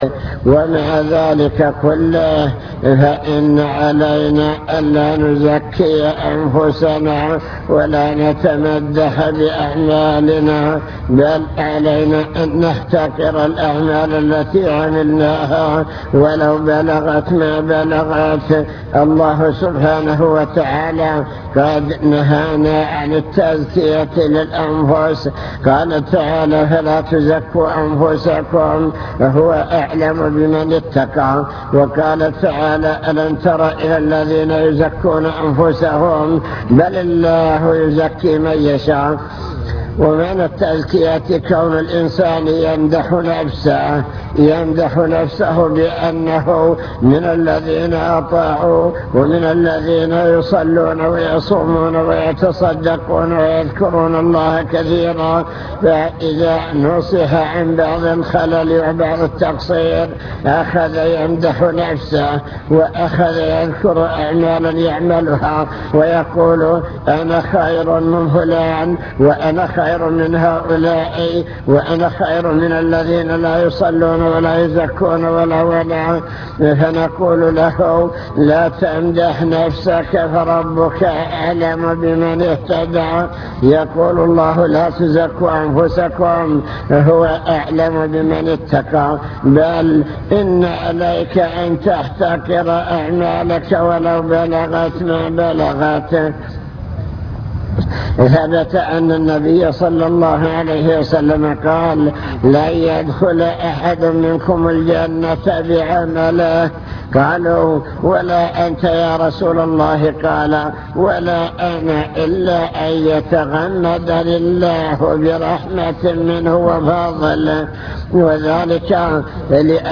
المكتبة الصوتية  تسجيلات - محاضرات ودروس  محاضرة في بني زيدان